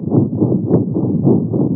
Kontinuierliches Geräusch: und mit PDA